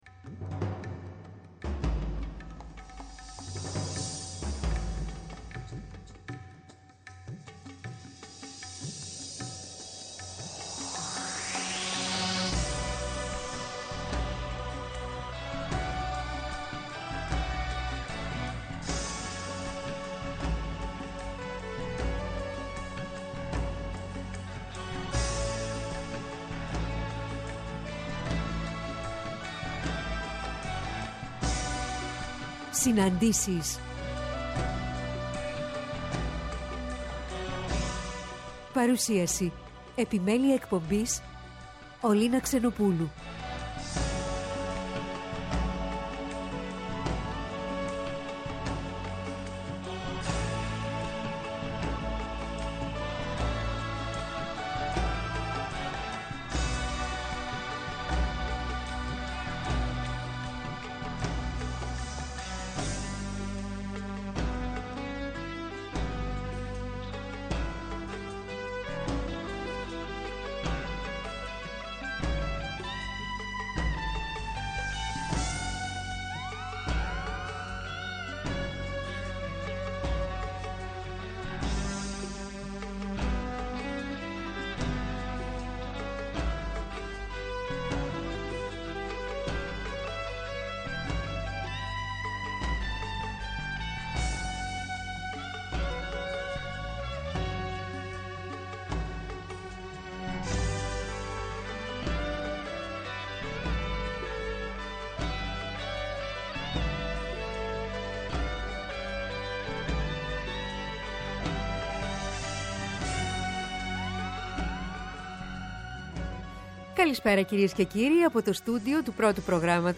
Αποκαλυπτικές Συναντήσεις με πρόσωπα της κοινωνικής, πολιτικής και πολιτιστικής τρέχουσας επικαιρότητας με τη μουσική να συνοδεύει, εκφράζοντας το «ανείπωτο».